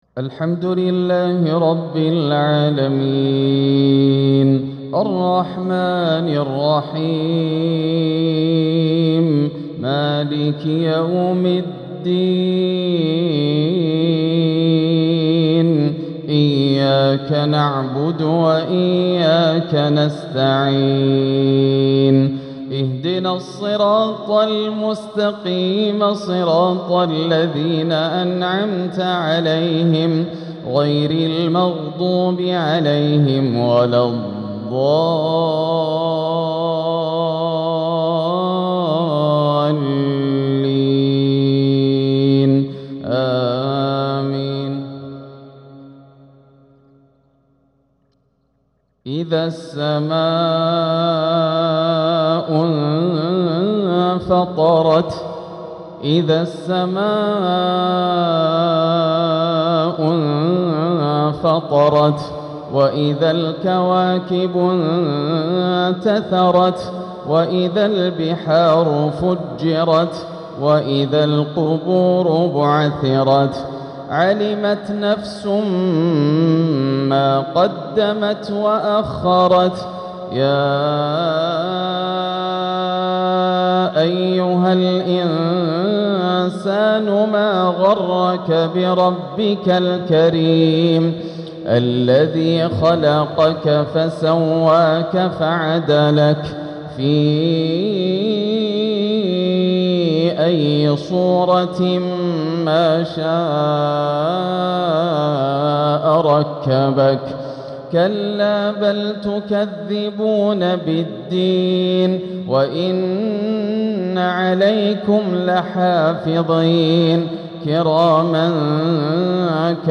تلاوة رائعة لسورة الانفطار | مغرب الاثنين 3-2-1447هـ > عام 1447 > الفروض - تلاوات ياسر الدوسري